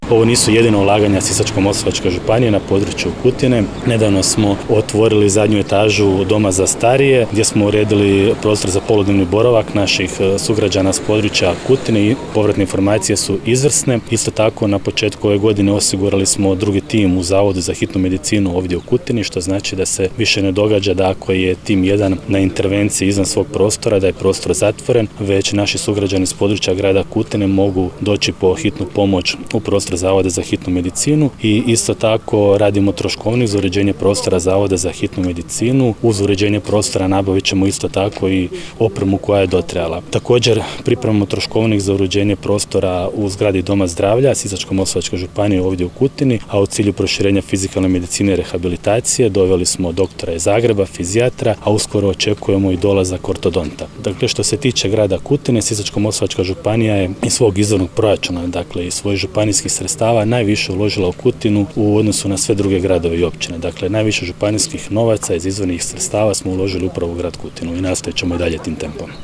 Župan napominje kako ovo nisu jedina ulaganja Sisačko-moslavačke županije na području Kutine te dodaje